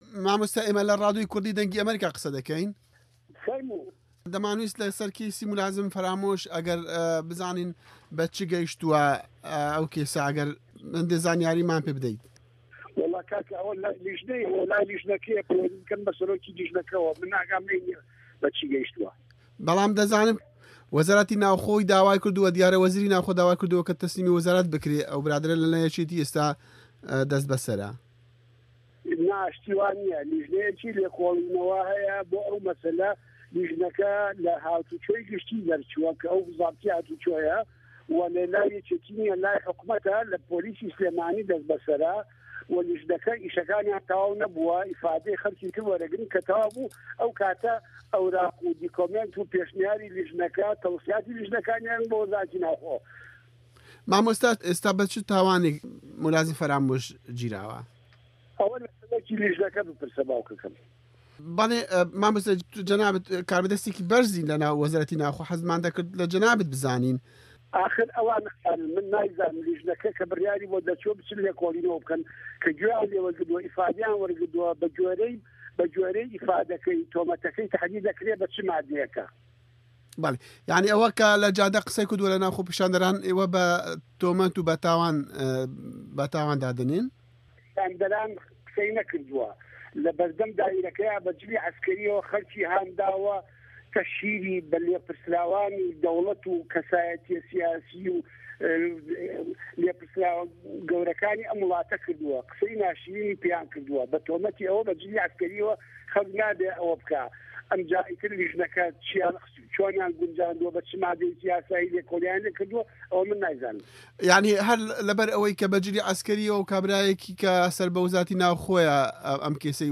وتووێژ لەگەڵ جەلال کەریم بریکاری وەزارەتی ناوخۆی هەرێم